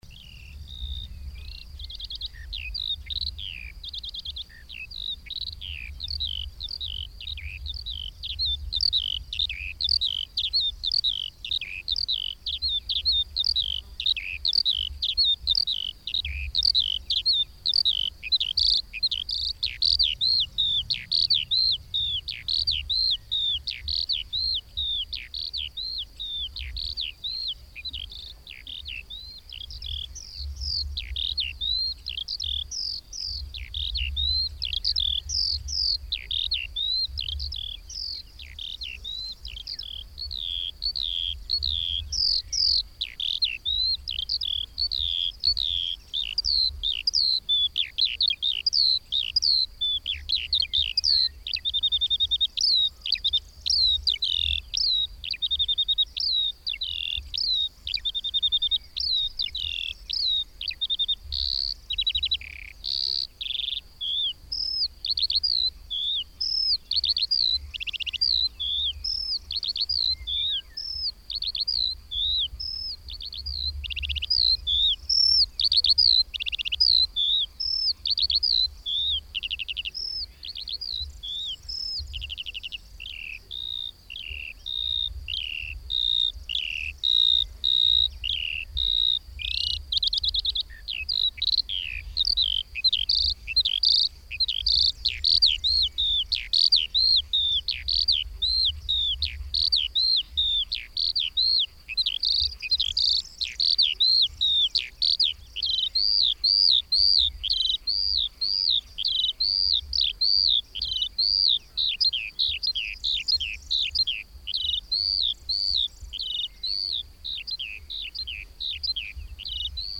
Cântecul și zborul deosebit al ciocârliei din perioada de înmulțire au atras atenția de milenii și în multe melodii populare sau culte este amintit trilul ciocârliei.
Ciocarlia-2-de-camp-.mp3